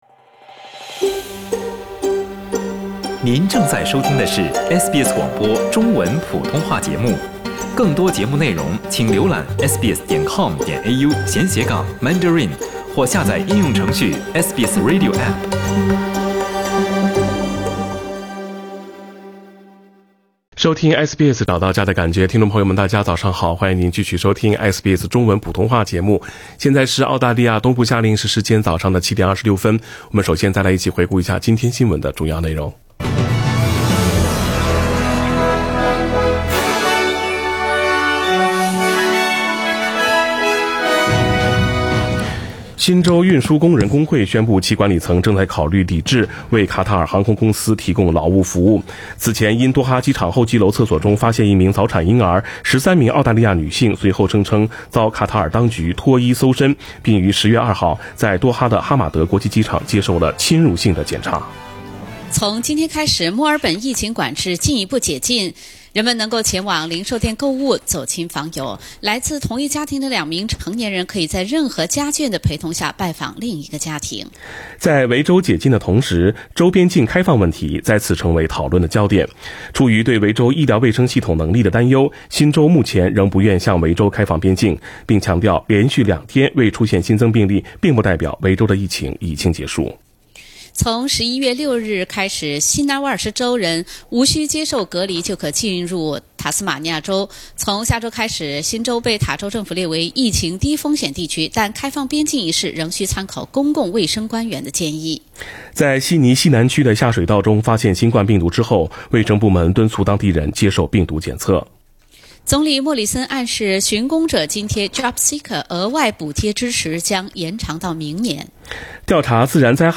SBS早新闻（10月28日）